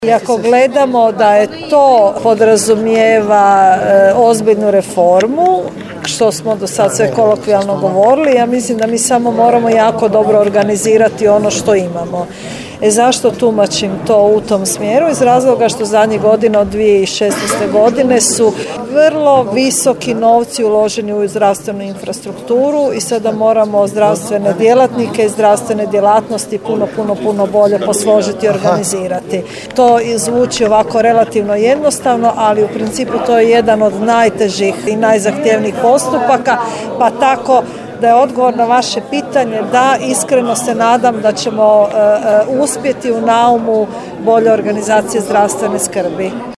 Razgovor je snimljen, recimo to tako, na marginama prošlotjednog sastanka o Razvojnom sporazumu za Sjever Hrvatske održanom u Daruvaru.